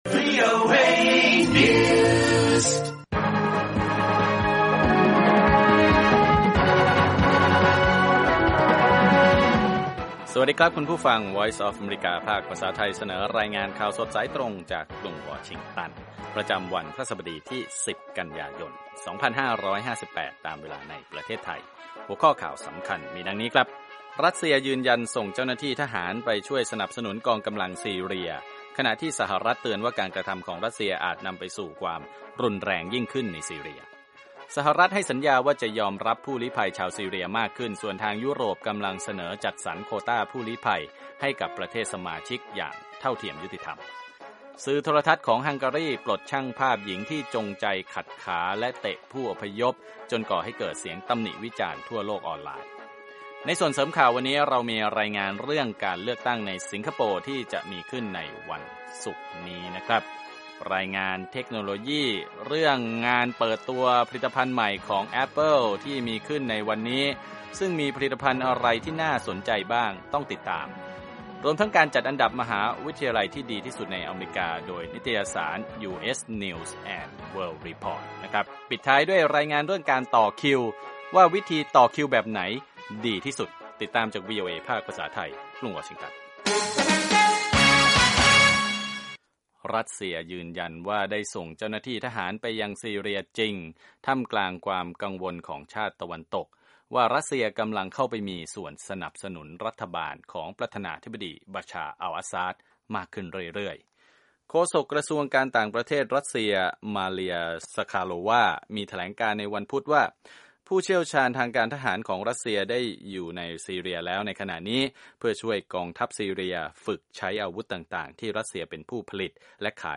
ข่าวสดสายตรงจากวีโอเอ ภาคภาษาไทย 8:30–9:00 น. วันพฤหัสบดีที่ 10 ก.ย 2558